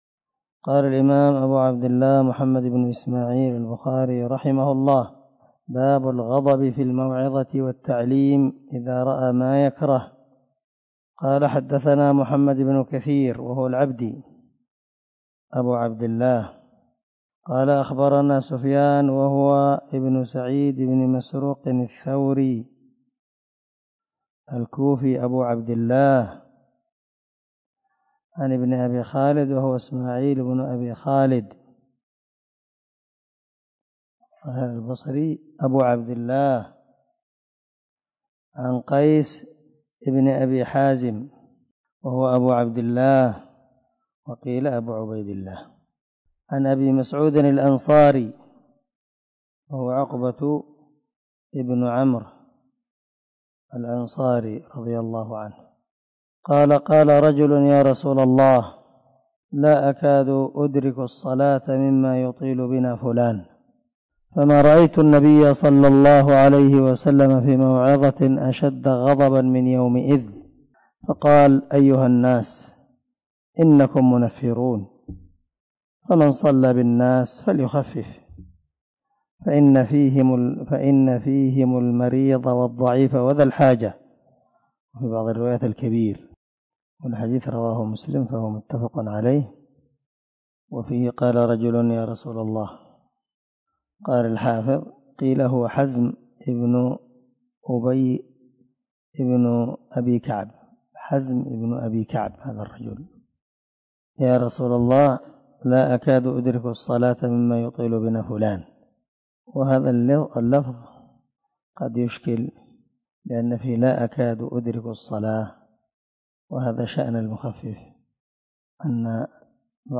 سلسلة_الدروس_العلمية
✒ دار الحديث- المَحاوِلة- الصبيحة.